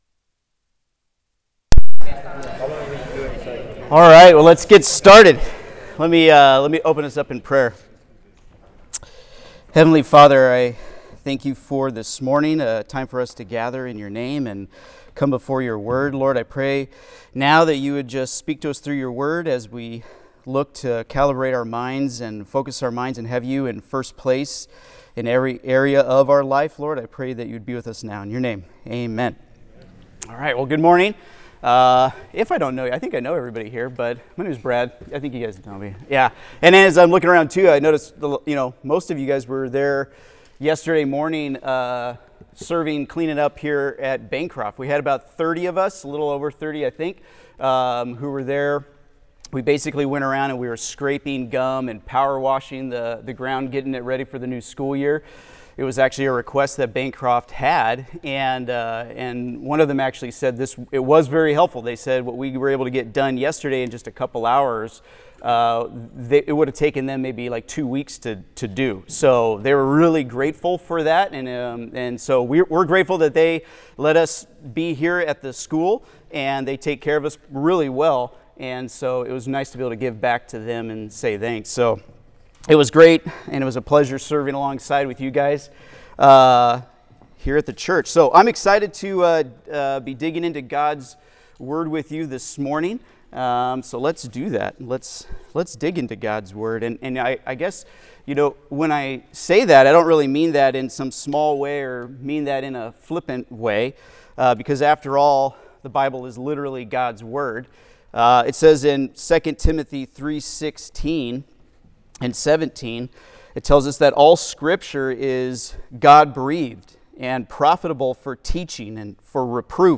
Equipped to Have Christ First in All Things - Part 1 (Sermon) - Compass Bible Church Long Beach